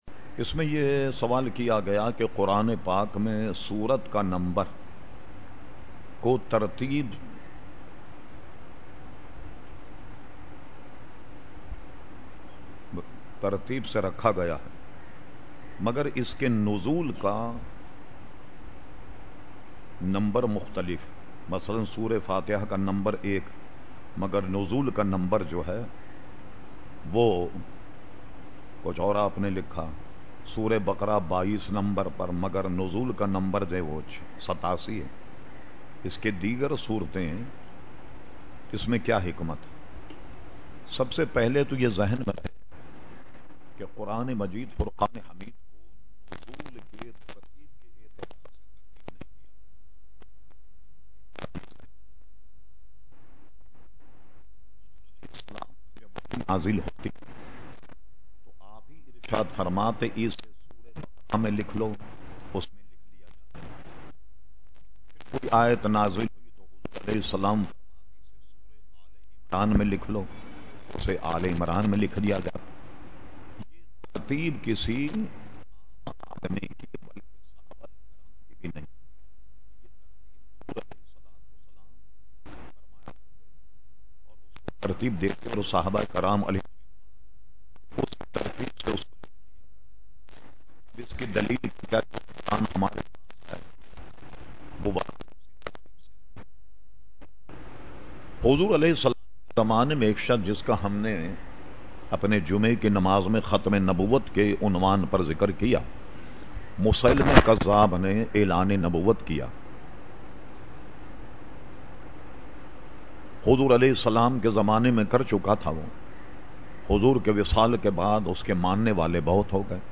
Q/A Program held on Sunday 26 September 2010 at Masjid Habib Karachi.